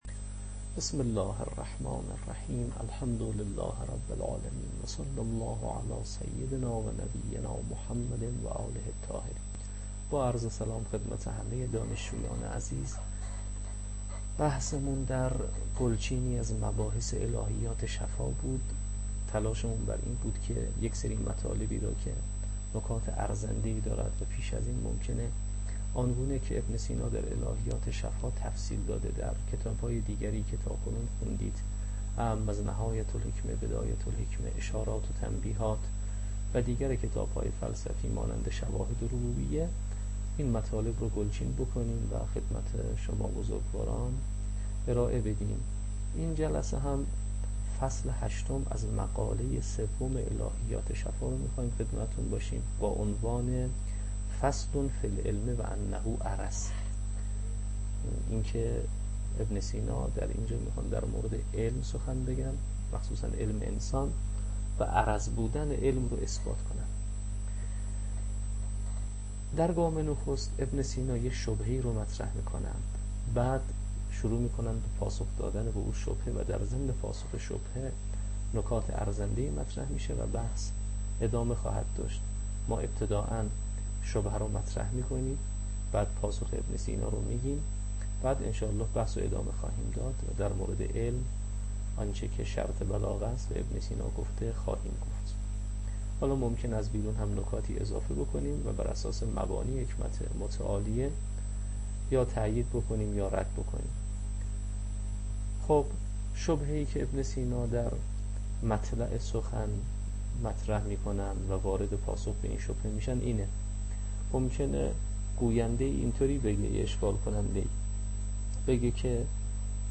الهیات شفاء، تدریس